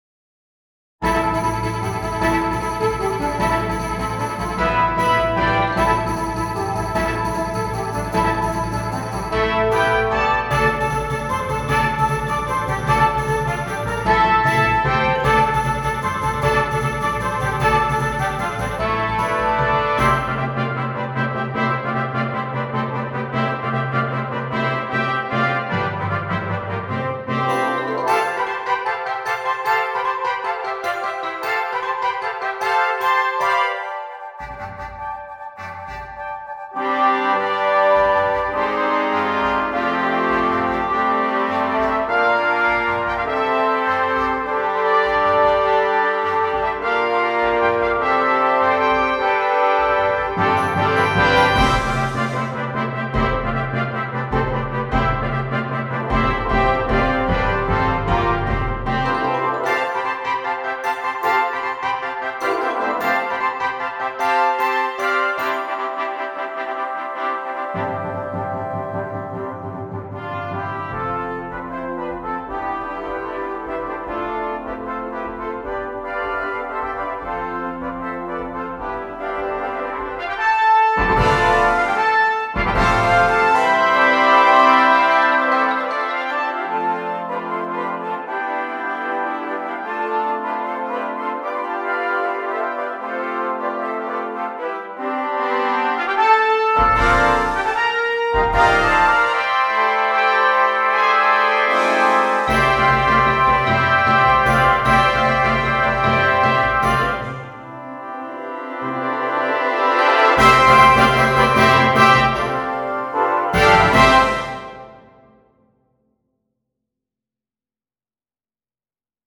20 Trumpets and Percussion